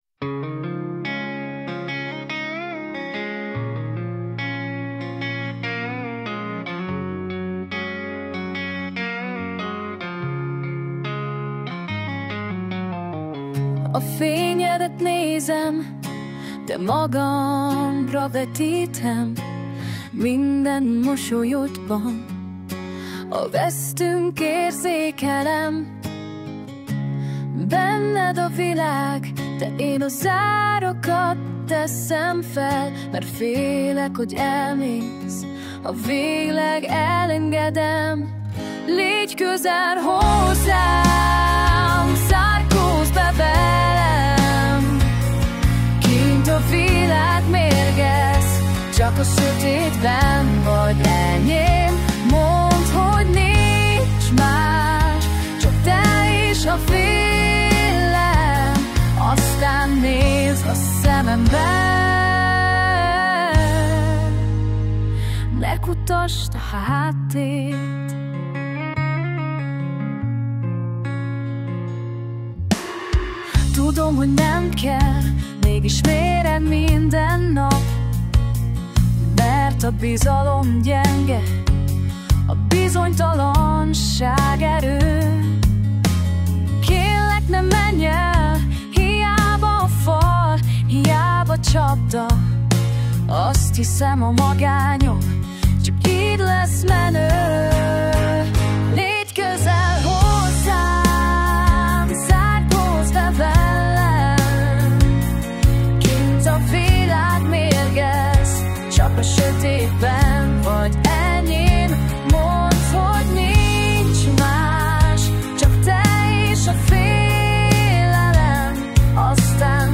VersZenés vers